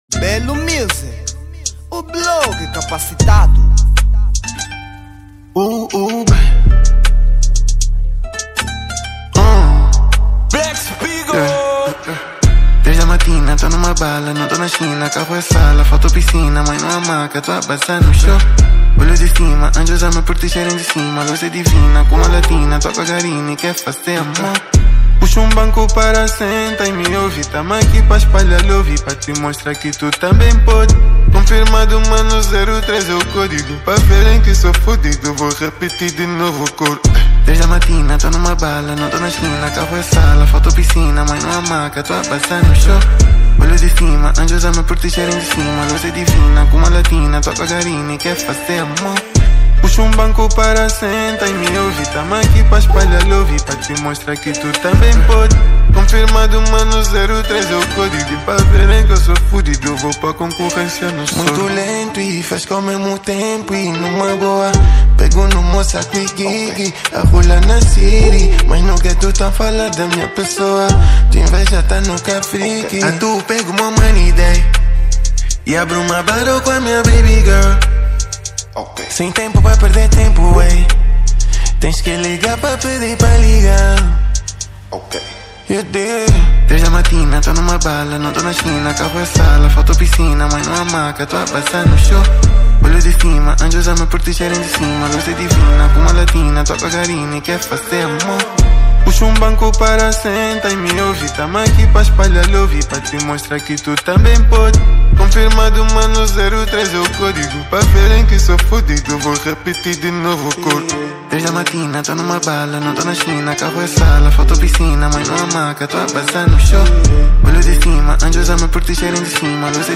Género : Trap